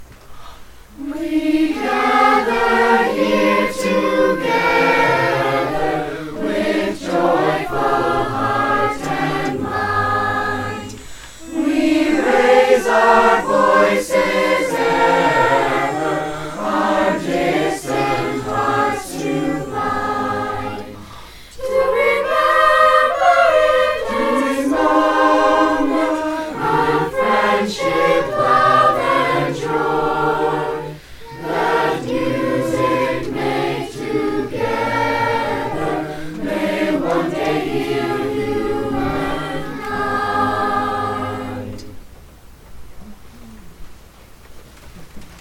the students
Chamber, Choral & Orchestral Music
Chorus
1Chorus.mp3